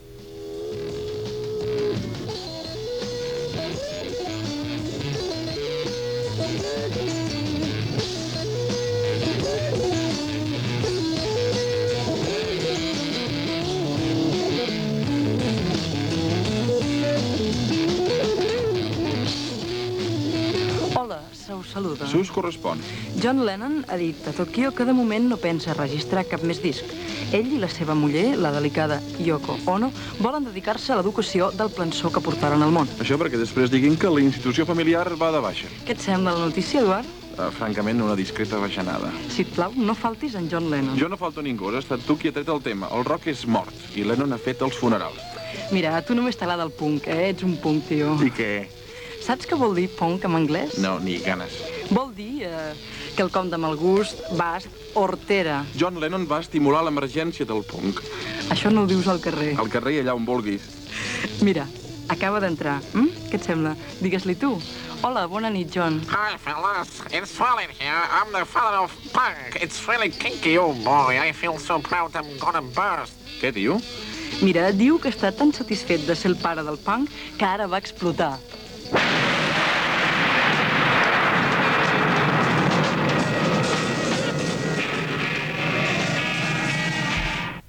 Comentari satíric sobre el músic John Lennon i els estils musicals del rock i el punk
Fragment extret del programa Radioactivitat emès l'1 d'octubre de 1985 per Ràdio 4.